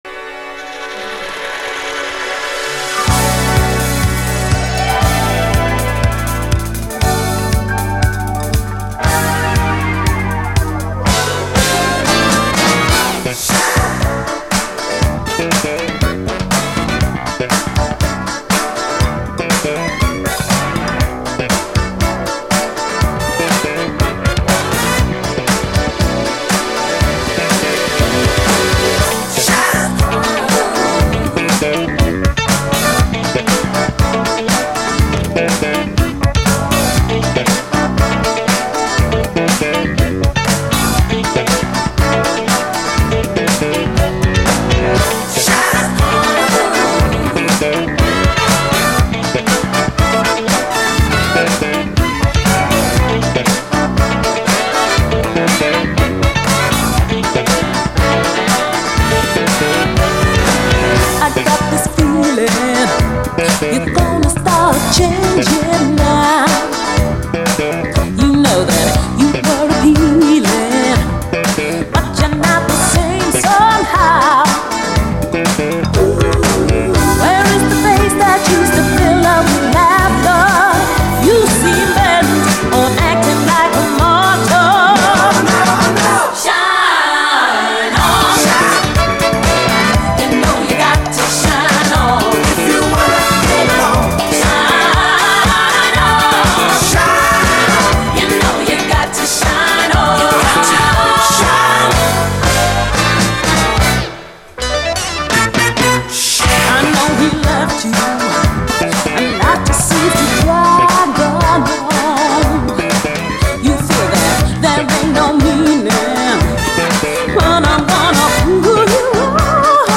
SOUL, 70's～ SOUL, DISCO
鋭く切り裂くUK産最高アーバン・シンセ・ブギー！
アーバンかつ切れ味鋭いUK産最高シンセ・ブギー！